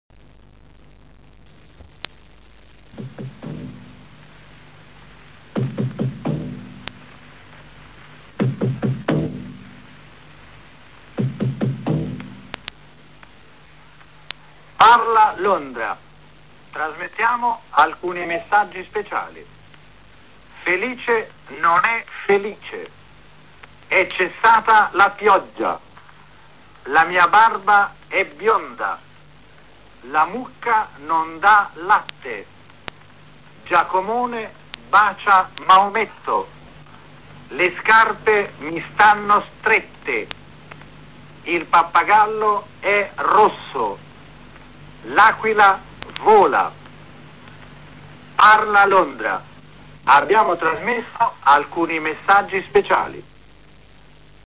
Original audio recording of a 1944 Italian BBC ‘crack’.